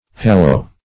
Hallow \Hal"low\ (h[a^]l"l[-o]), v. t. [imp. & p. p.